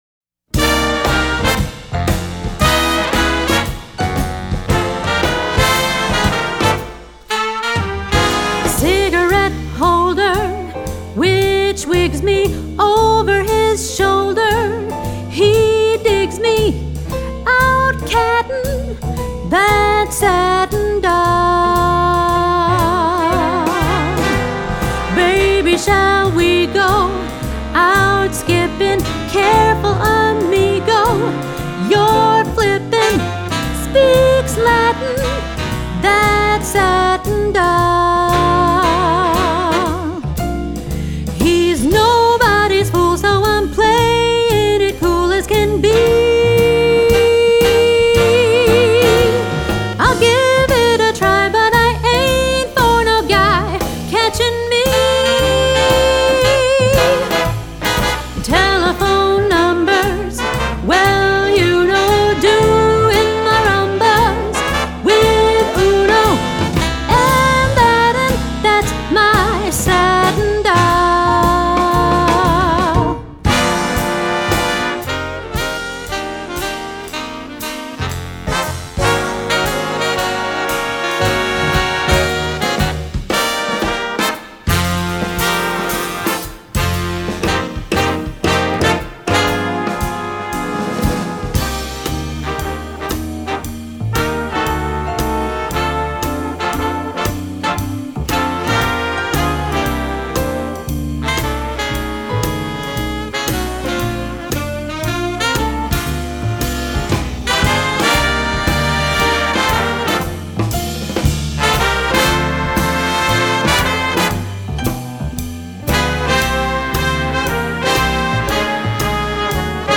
jazz, standards
Vocal solo part: